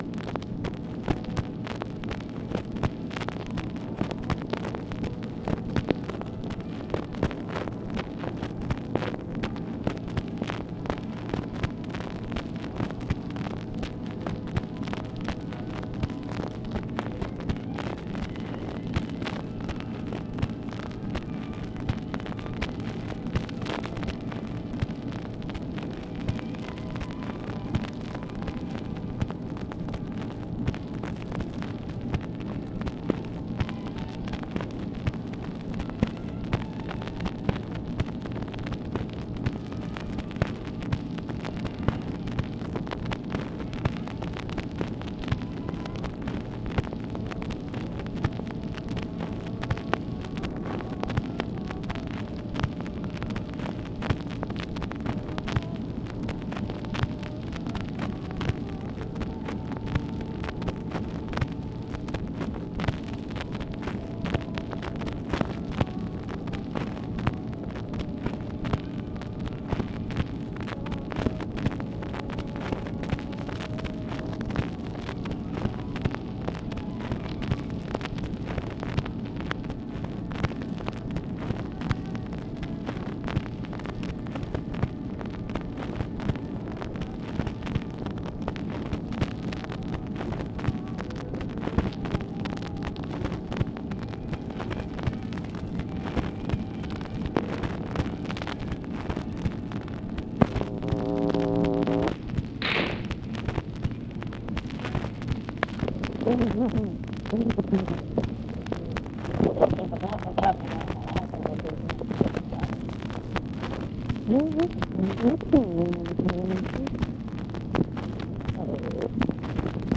OFFICE CONVERSATION, August 26, 1964